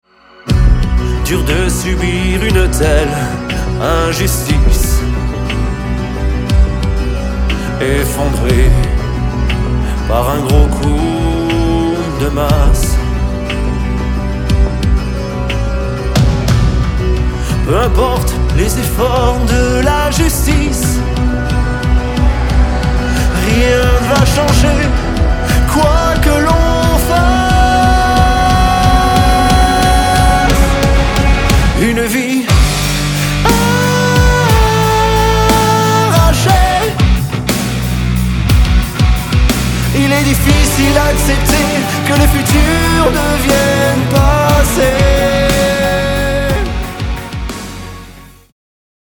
choeurs